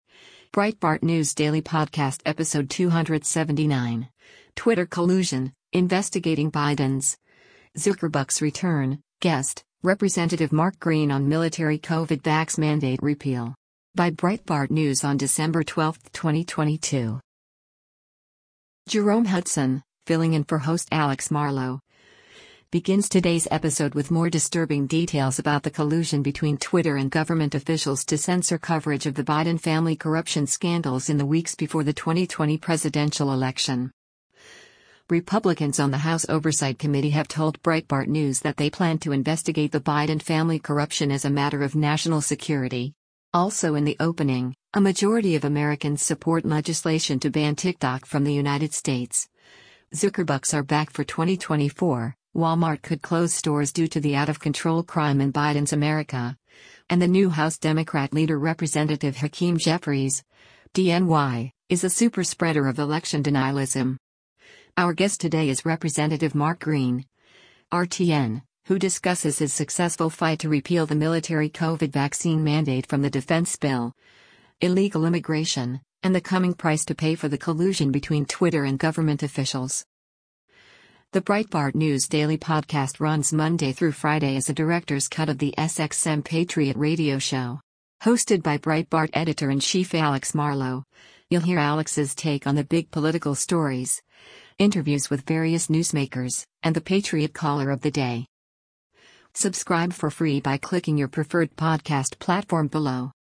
Our guest today is Rep. Mark Green (R-TN), who discusses his successful fight to repeal the military COVID vaccine mandate from the defense bill, illegal immigration, and the coming price to pay for the collusion between Twitter and government officials.
The Breitbart News Daily Podcast runs Monday through Friday as a “Director’s Cut” of the SXM Patriot radio show.